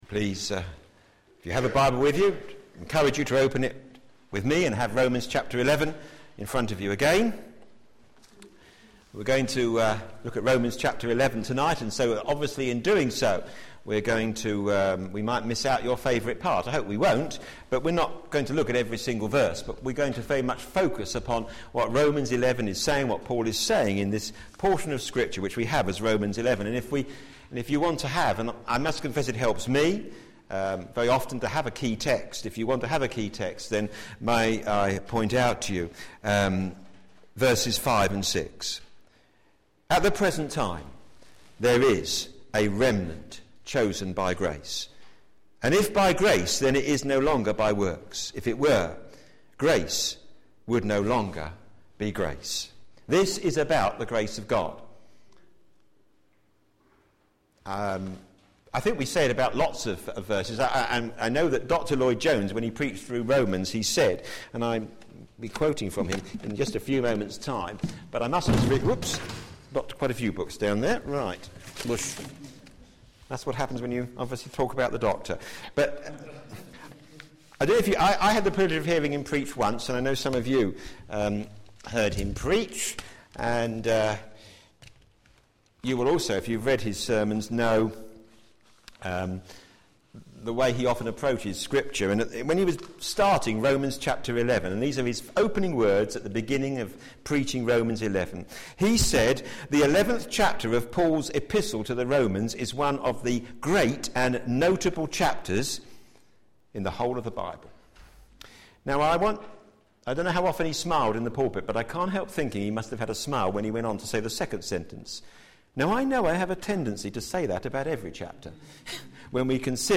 Media Library Media for p.m. Service on Sun 06th May 2012 18:30 Speaker
Romans Theme: Israel's Rejection; Not Total Sermon In the search box below